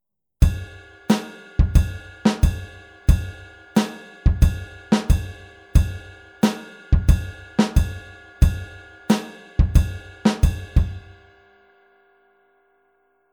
Rechte Hand wieder auf dem Kopfbecken